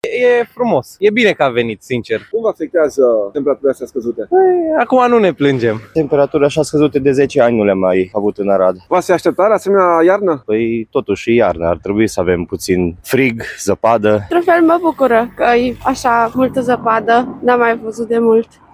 În paralel, câțiva arădeni au apreciat vremea și zăpada, pentru că nu au mai prins de multă vreme o iarnă geroasă: